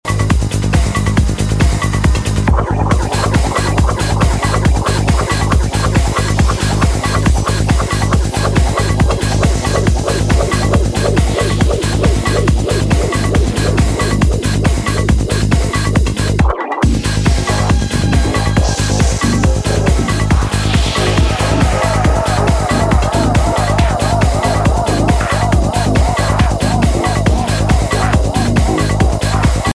Driving psyish ID